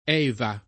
vai all'elenco alfabetico delle voci ingrandisci il carattere 100% rimpicciolisci il carattere stampa invia tramite posta elettronica codividi su Facebook Eva [ $ va ; ingl. & ivë ; sp. %B a ; ted. % efa o % eva ] pers. f. — sim., in it., i cogn.